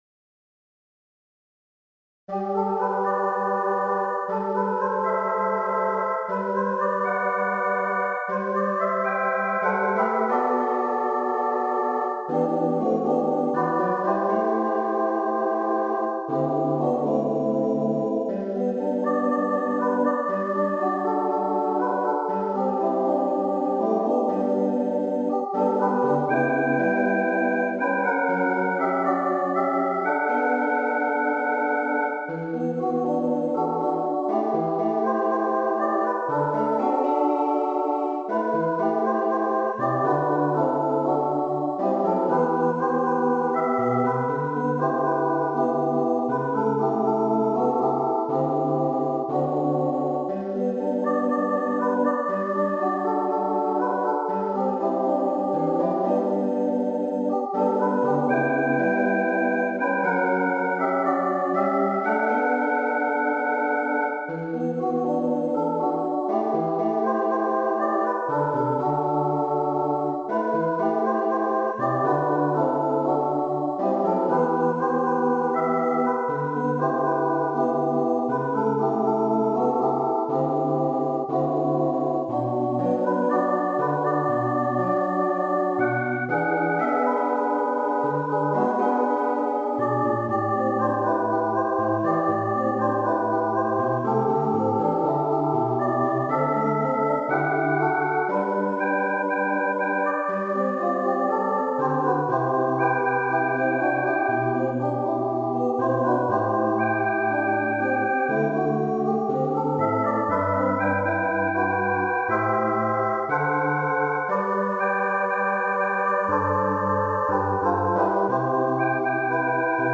ア・カペラVer.
4枚ボーカルで書いてます。
1コーラス～Cメロ～サビ～エンディングの構成